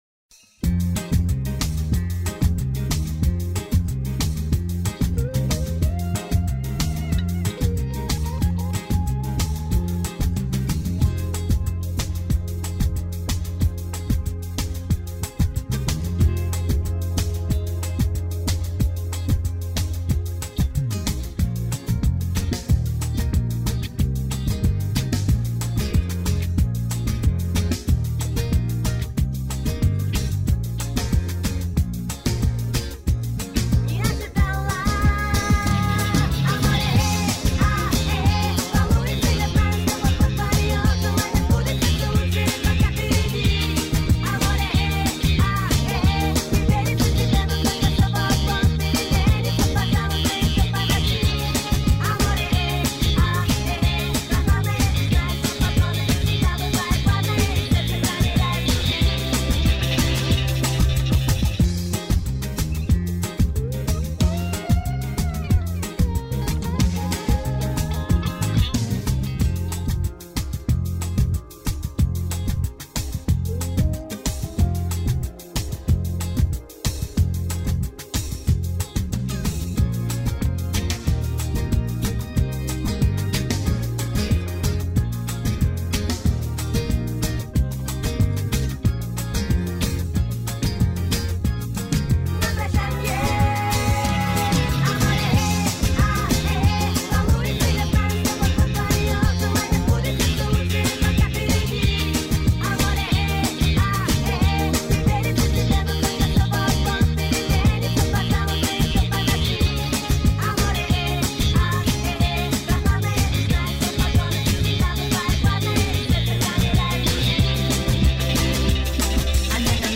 минусовка версия 216646